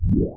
low_whoosh.ogg